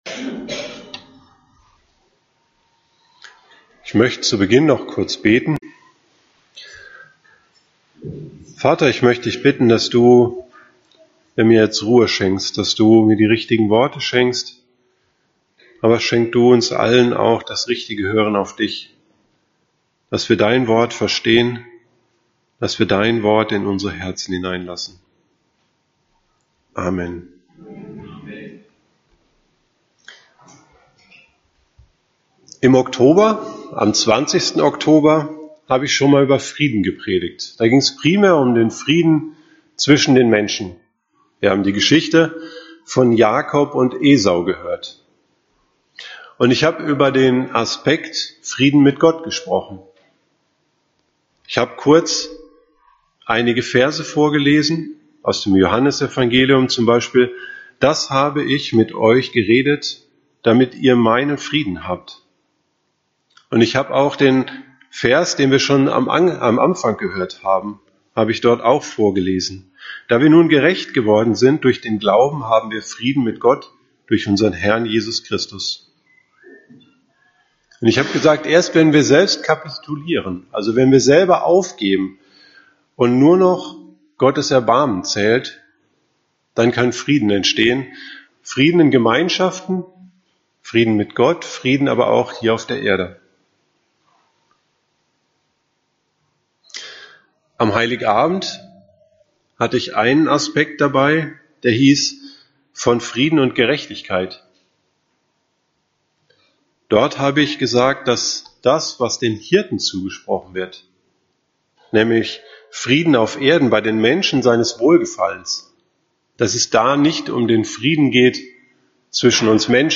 Predigtserie